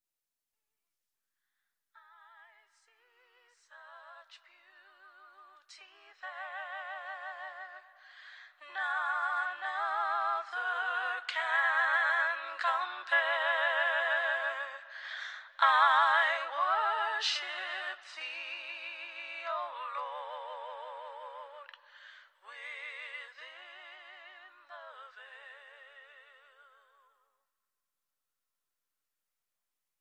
• Sachgebiet: Black Gospel